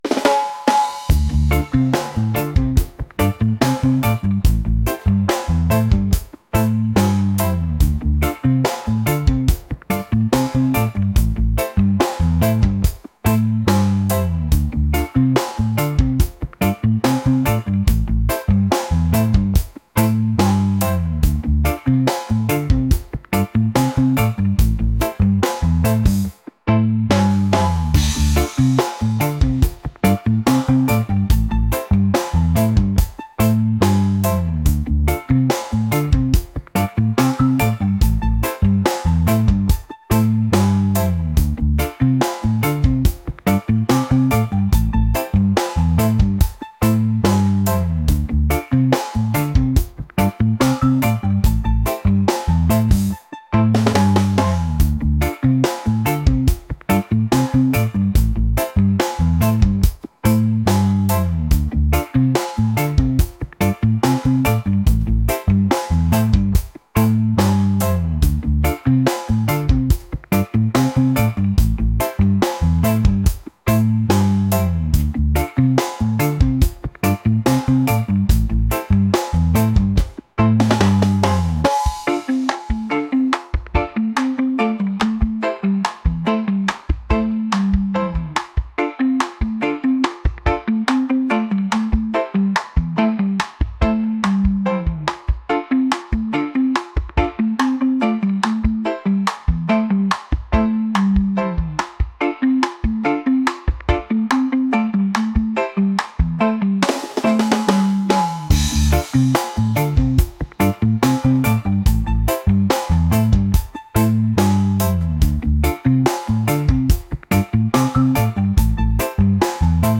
reggae | fusion | upbeat